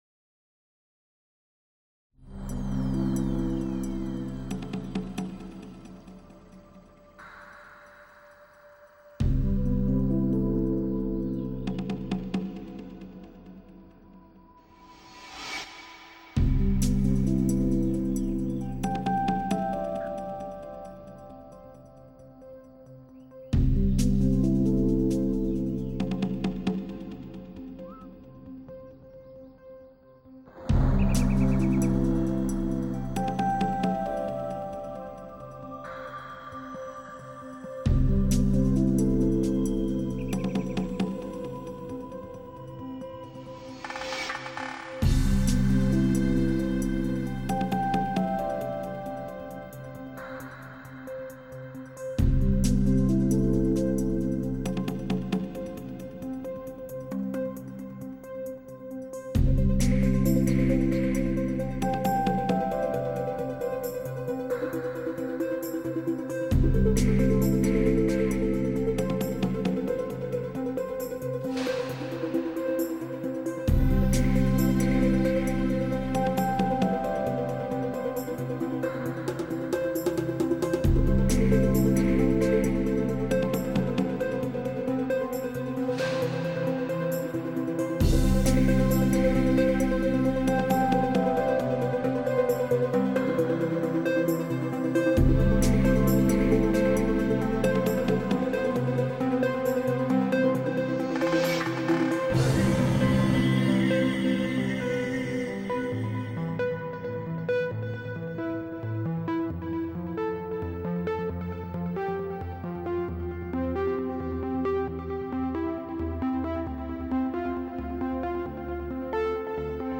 Trance_1.mp3